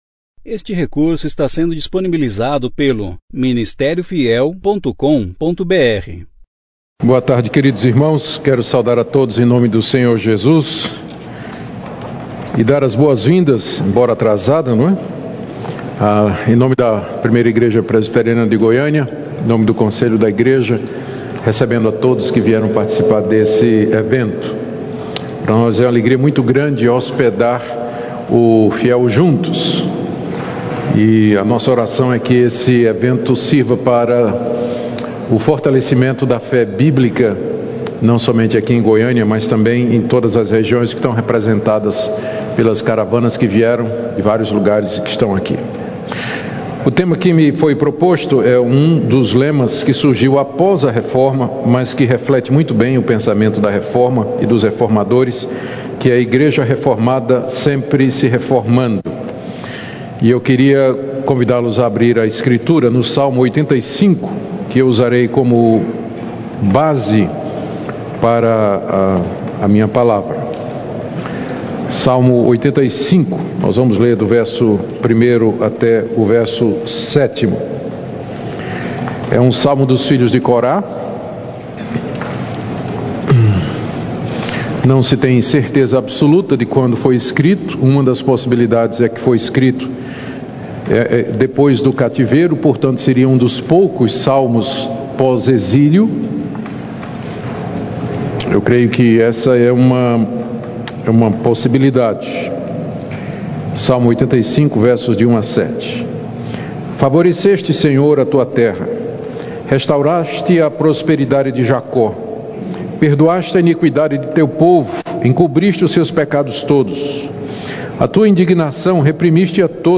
Conferência: Juntos em Cristo – Goiânia Tema: Protestantes Ano